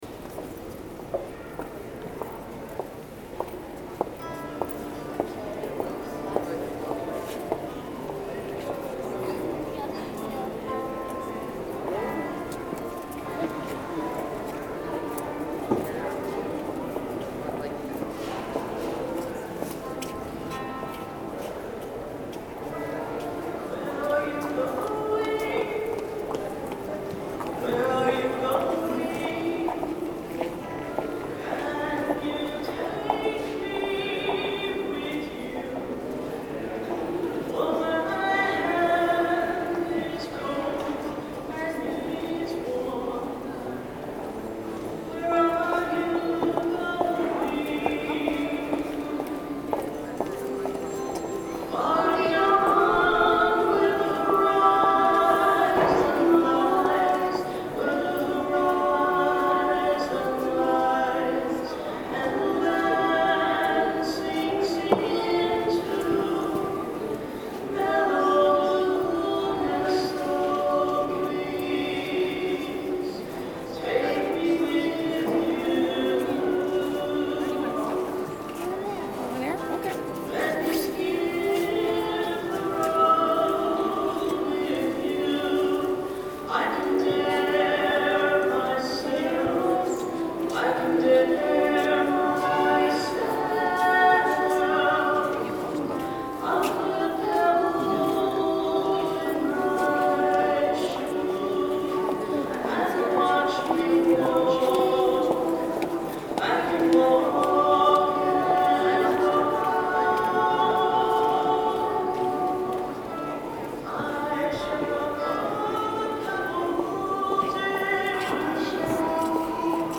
Music from the 10:30 Mass on the 1st Sunday in Lent, March 24, 2013:
(Note: The vocals overpower the 6 string guitar. You can tell by the loudness of the ambient noise that the overall level of mic/guitar are too soft.)